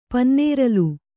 ♪ pannēralu